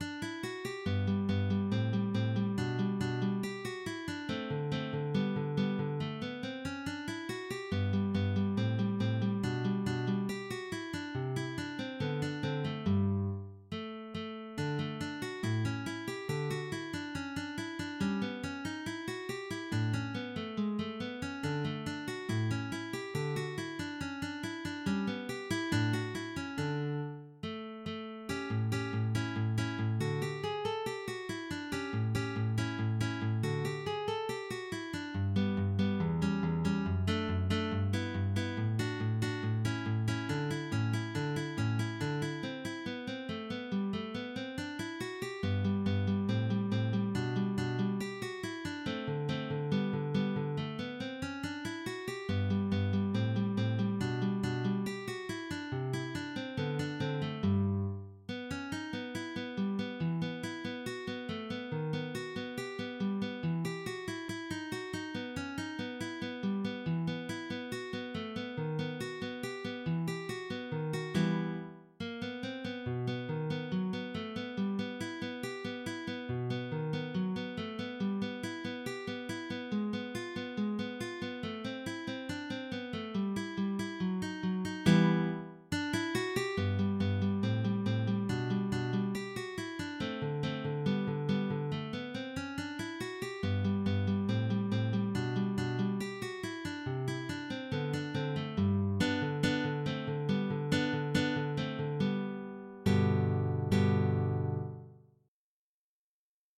op 27 pag 24 – rondo in Sol***[tab][-♫-][tg]
carulli_op_027_metodo-pag24-rondo-in-Sol.mid.mp3